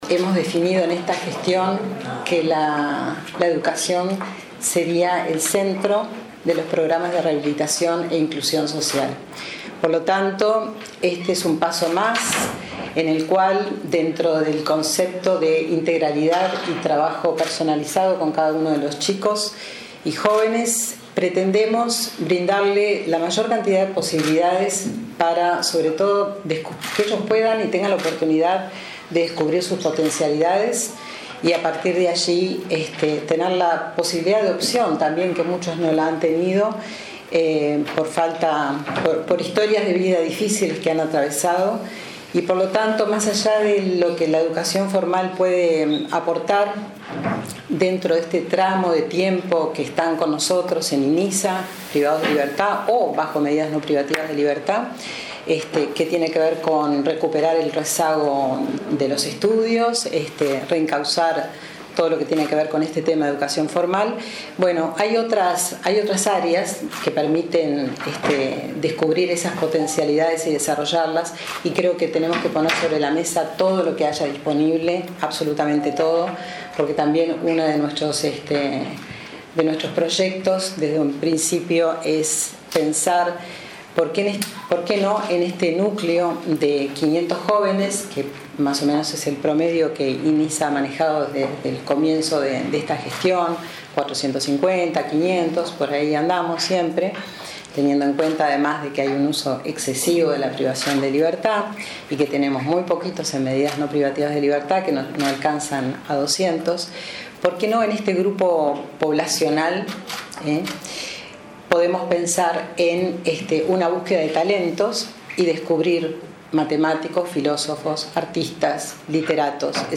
Tras un acuerdo entre el Ministerio de Educación y Cultura y el Instituto Nacional de Inclusión Adolescente, adolescentes y jóvenes del Inisa accederán a cursos de educación no formal. “La educación es el centro de los programas de rehabilitación que se desarrollan en el Instituto”, dijo la presidenta del instituto, Gabriela Fulco.